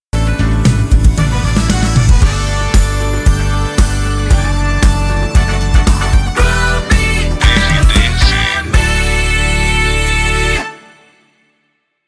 Volta de Bloco Curta